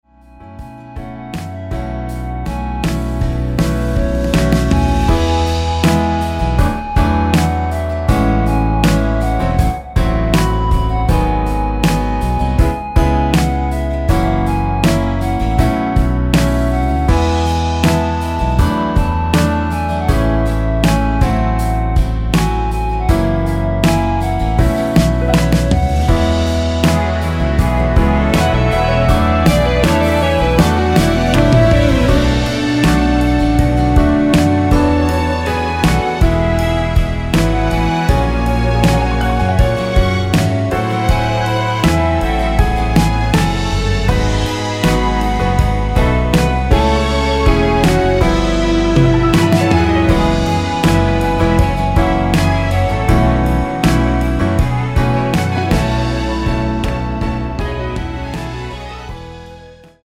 원키 2절 삭제한 멜로디 포함된 MR 입니다.(미리듣기및 본문의 가사 참조)
엔딩이 페이드 아웃이라 라이브 하시기 좋게 엔딩을 만들어 놓았습니다.
앞부분30초, 뒷부분30초씩 편집해서 올려 드리고 있습니다.